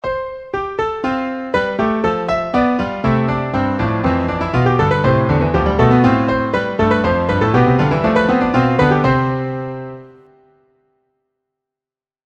Textura polifónica. Ejemplo.
polifónico
piano
canon
contrapunto
mayor